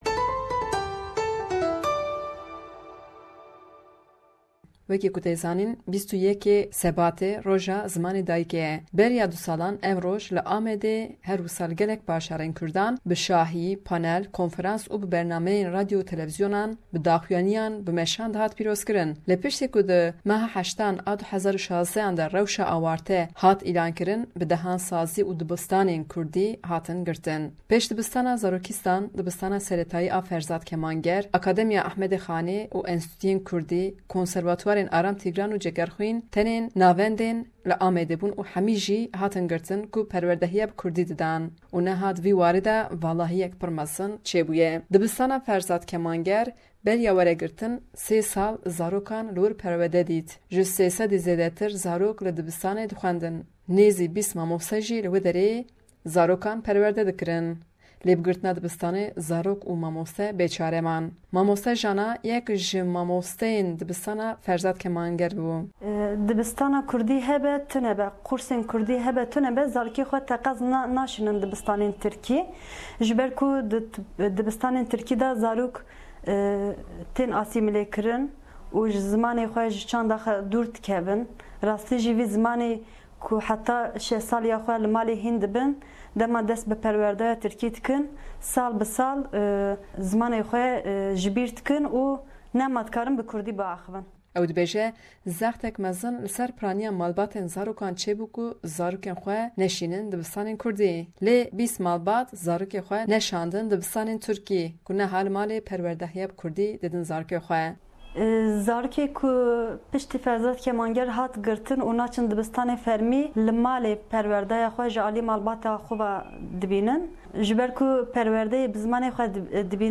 Raporta peyamnêra me ji Amedê